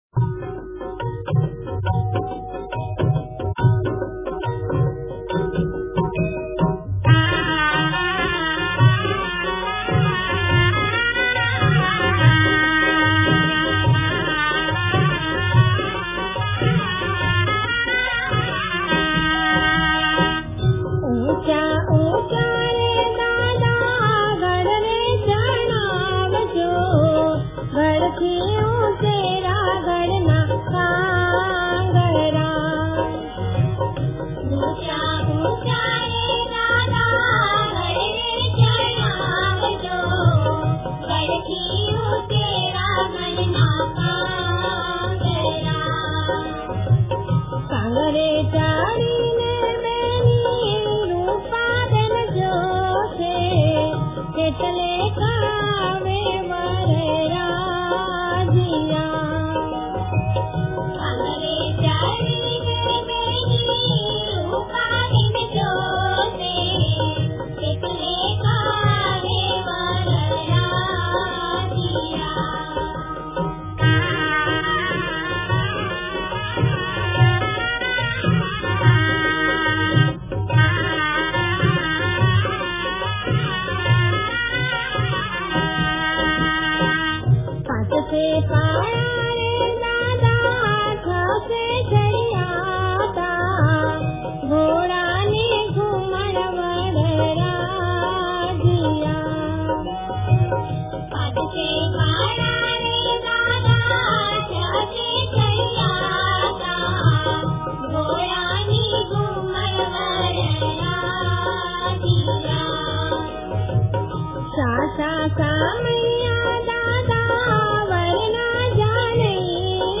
ગીત સંગીત લોક ગીત (Lok-Geet)